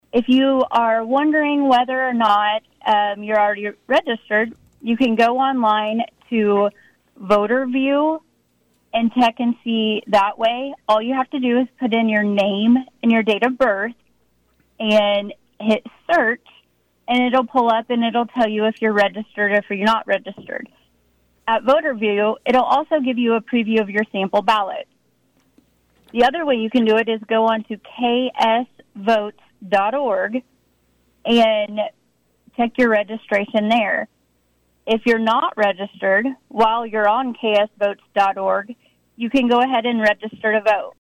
With that in mind, KVOE began what will be a recurring segment over the next several weeks on KVOE’s Morning Show, Monday, featuring Lyon County Election Officer Amie Jones.